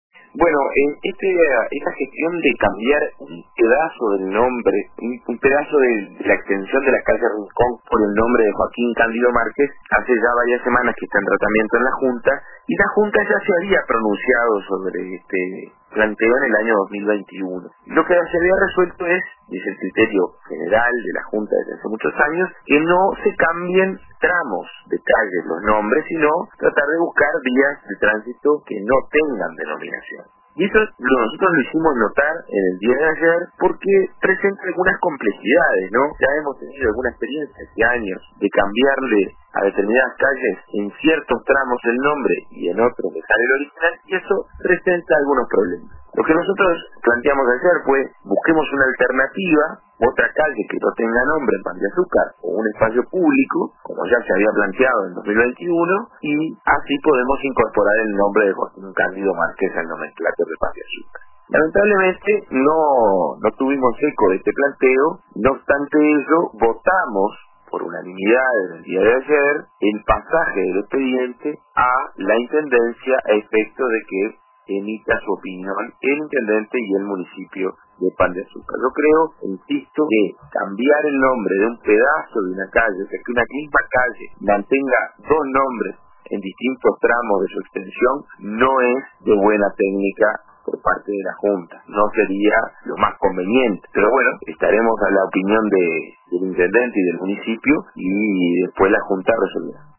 El edil Joaquín Garlo lo habló también en el programa “RADIO CON TODOS” de RADIO RBC